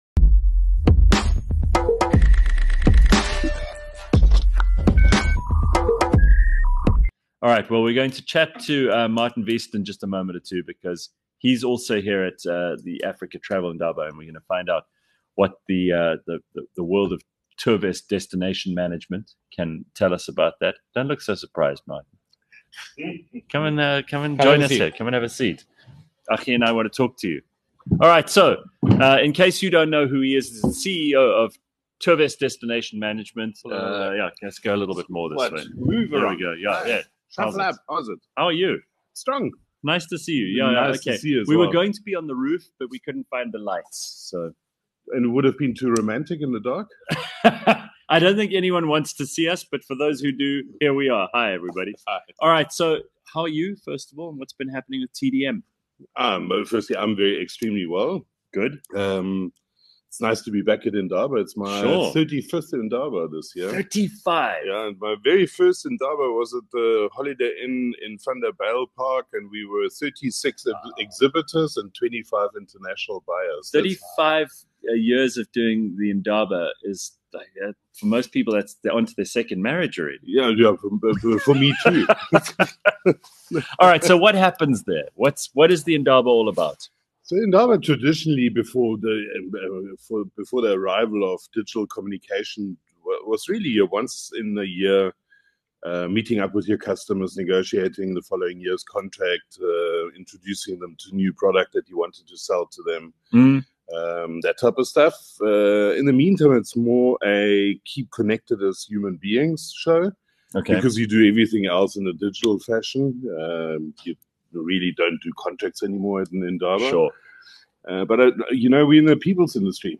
for a captivating conversation about the magic of South Africa as a world-class travel destination. From breathtaking landscapes to vibrant cultural experiences, they unpack what truly sets the country apart.